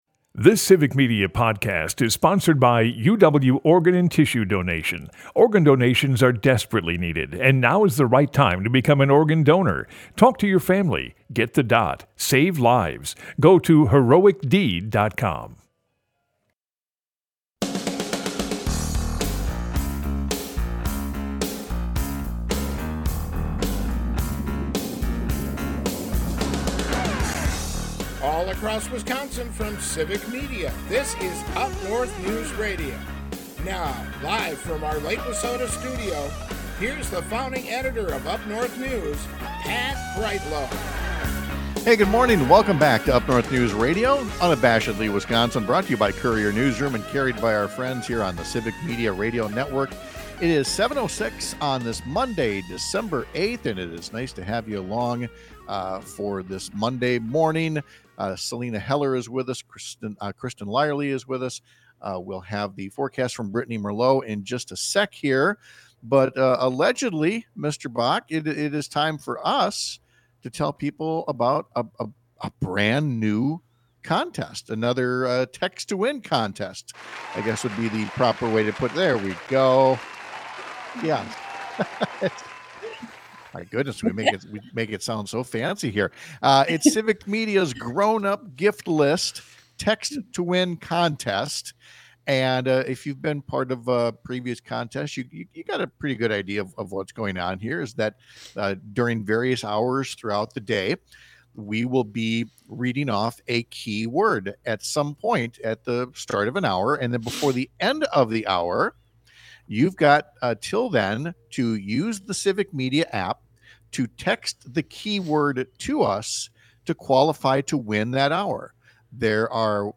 Broadcasts live 6 - 8 a.m. across the state!